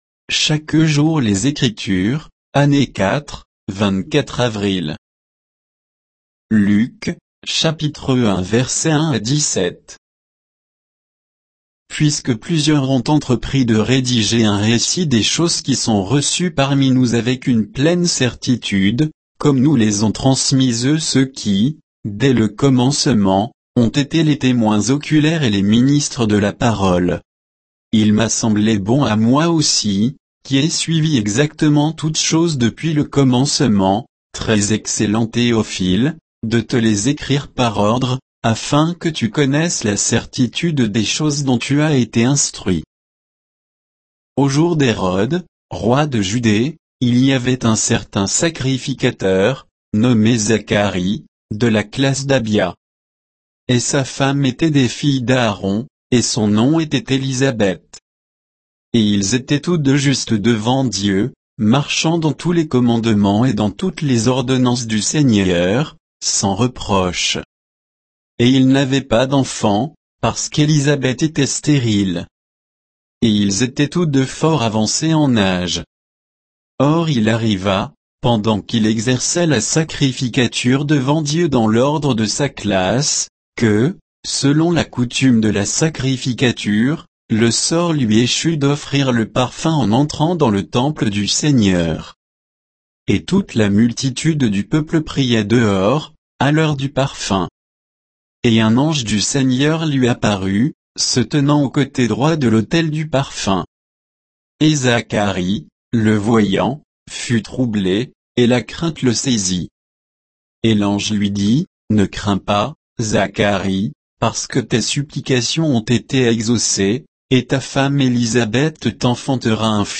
Méditation quoditienne de Chaque jour les Écritures sur Luc 1, 1 à 17